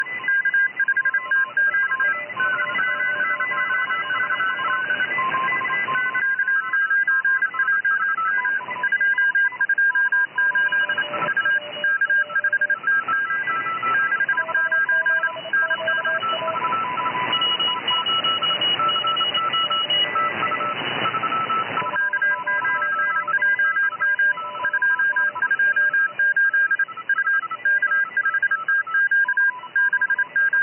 Simple morse code word
morse.mp3